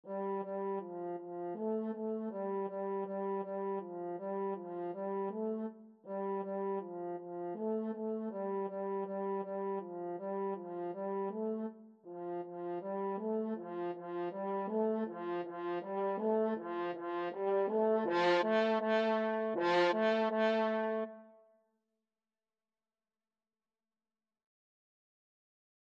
• Cello
F4-A4
2/4 (View more 2/4 Music)
Instrument:
Classical (View more Classical French Horn Music)